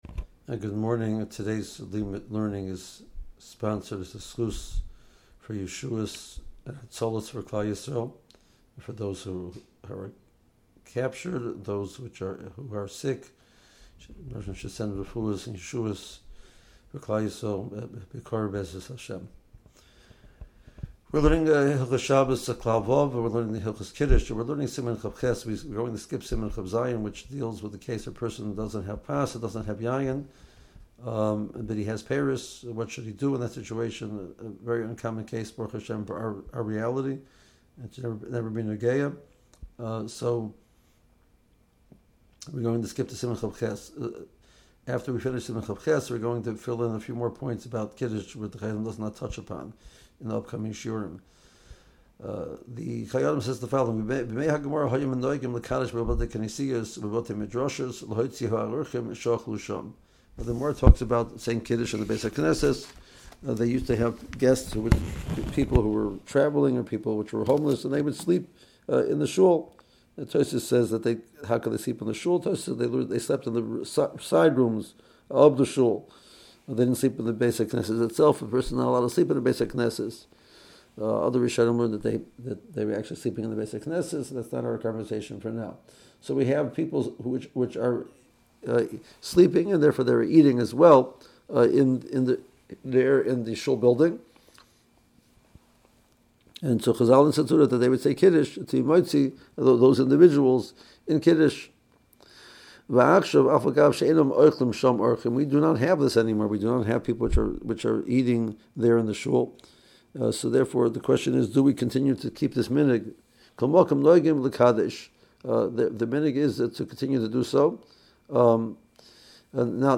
Today’s shiur is sponsored as a zechus for yeshuos and hatzalos for Klal Yisroel, for those in captivity and those who are ill.